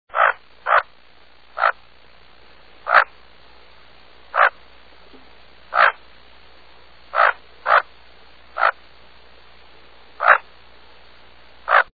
Bufflehead
Bufflehead.mp3